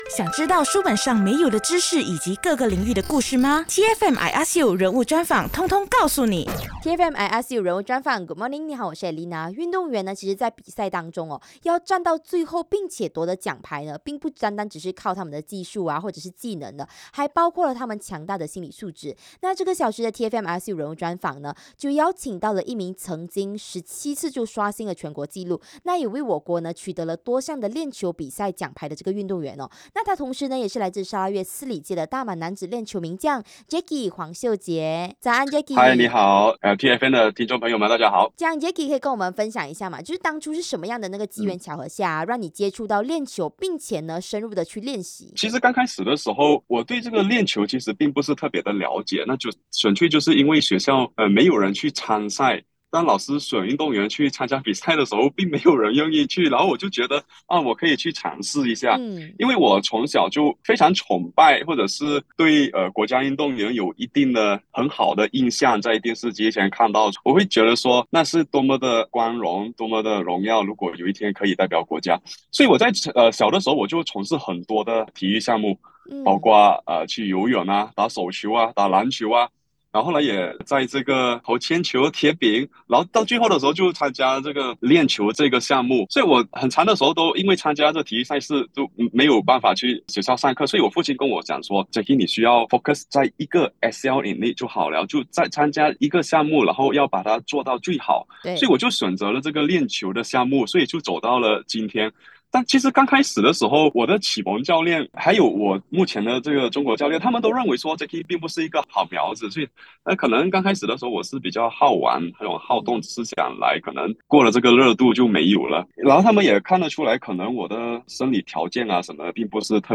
人物专访 大马链球选手